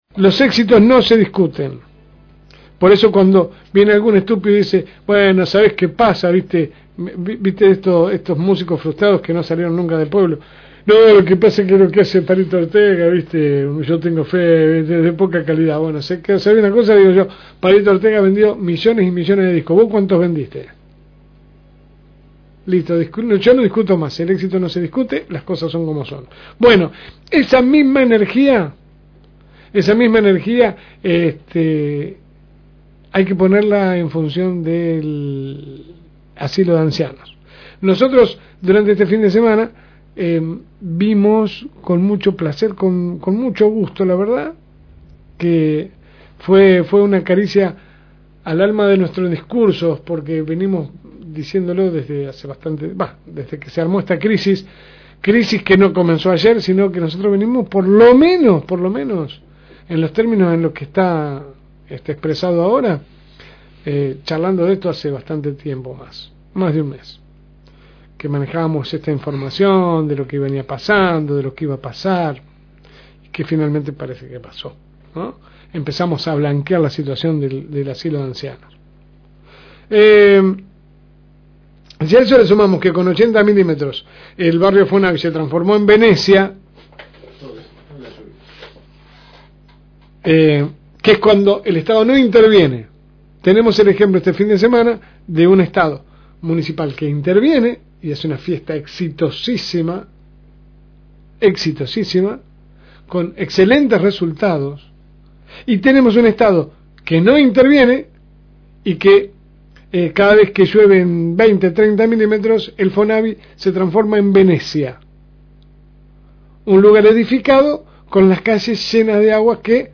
AUDIO – Editorial de La Segunda Mañana